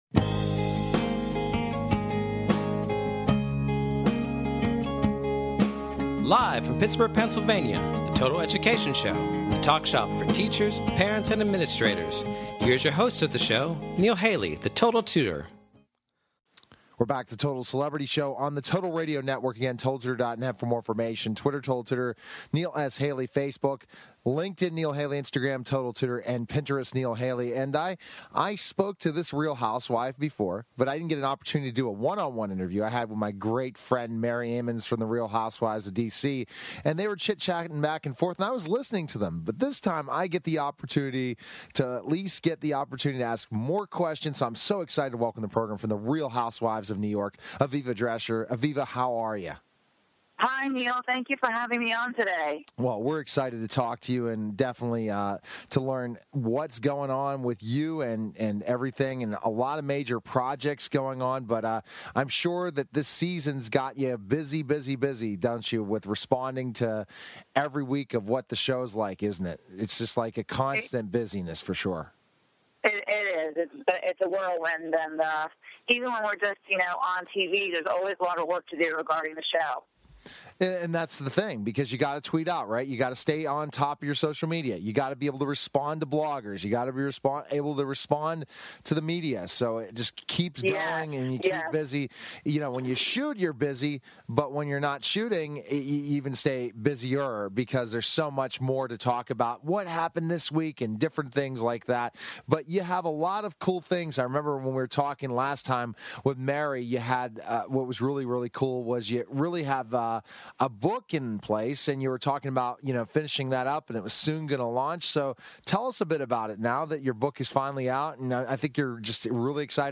Talk Show Episode, Audio Podcast, Total_Education_Show and Courtesy of BBS Radio on , show guests , about , categorized as